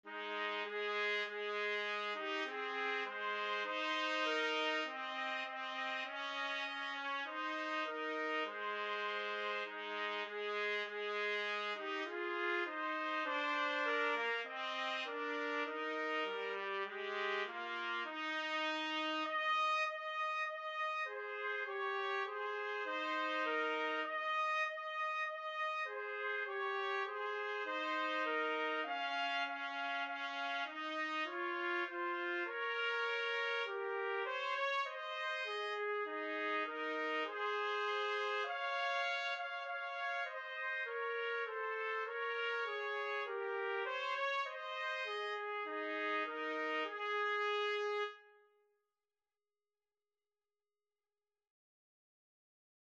4/4 (View more 4/4 Music)
Trumpet Duet  (View more Easy Trumpet Duet Music)
Classical (View more Classical Trumpet Duet Music)